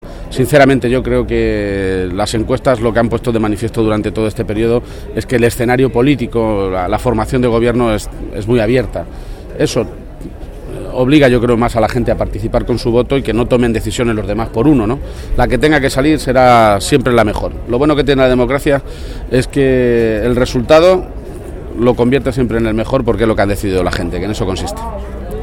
García-Page, que ha realizado estas manifestaciones tras ejercer su derecho al voto en el colegio público “Ciudad de Nara” de la capital regional, ha afirmado que la decisión que se tome hoy es muy importante.
Cortes de audio de la rueda de prensa